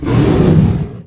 CosmicRageSounds / ogg / general / combat / creatures / dragon / he / hurt2.ogg
hurt2.ogg